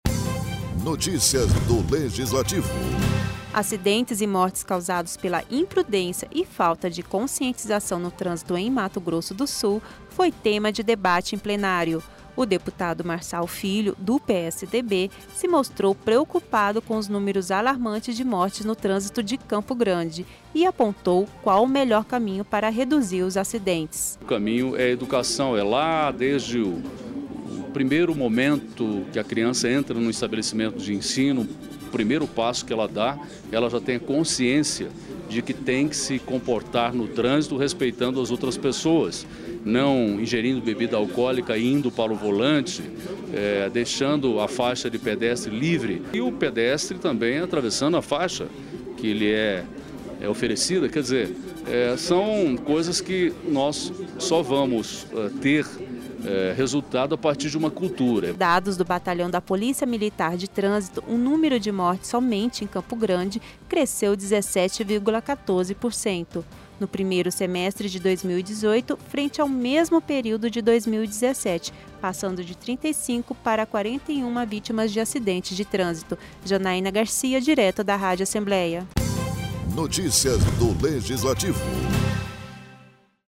Na sessão, desta terça-feira, o deputado Marçal Filho (PSDB) usou a tribuna e lamentou os casos de acidentes e mortes provocados por imprudência e falta de conscientização no trânsito.